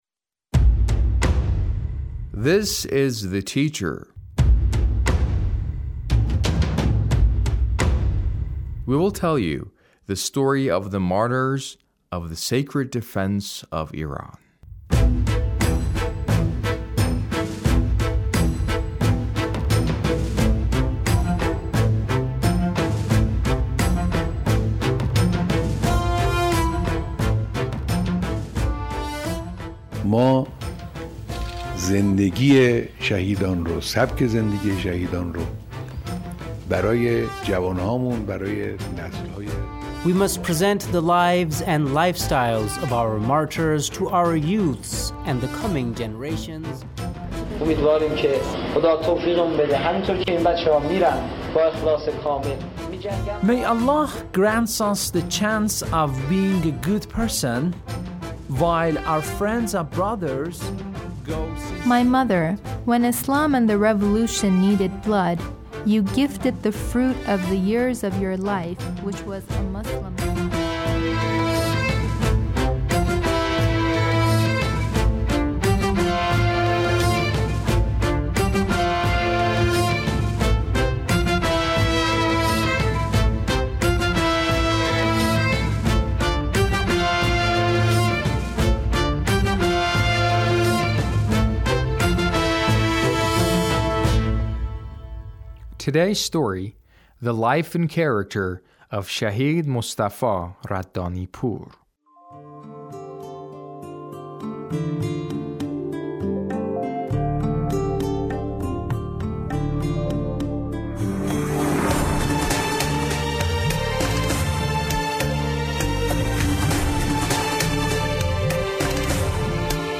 A radio documentary on the life of Shahid Mostafa Raddanipour- Part 1